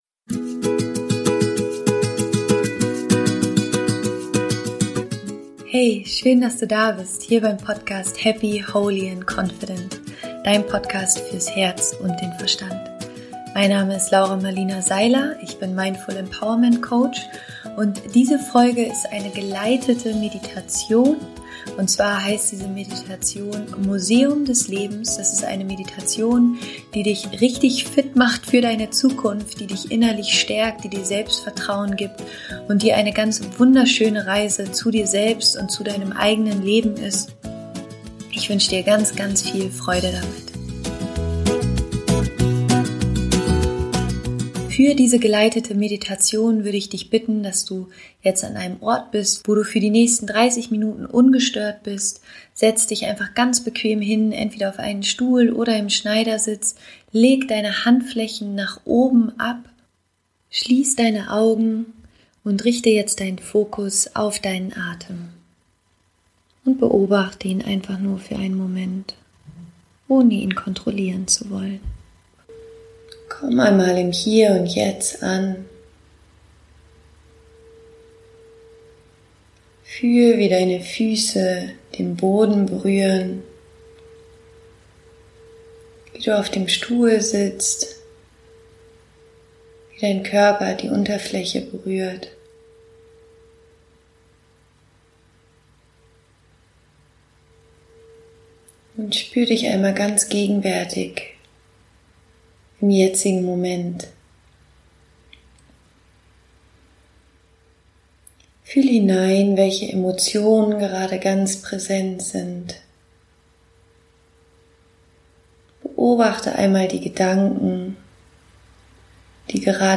Die geleitete Meditation wird dich empowern und dir Kraft und Zuversicht für die Zukunft geben.